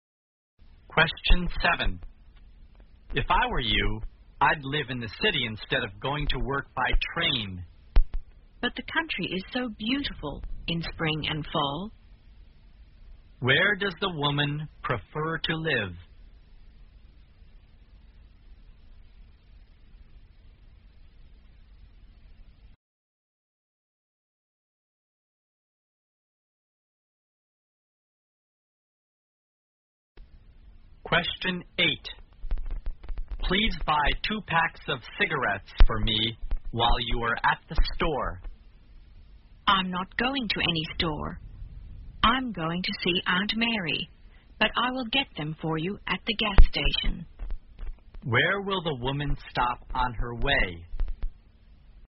在线英语听力室183的听力文件下载,英语四级听力-短对话-在线英语听力室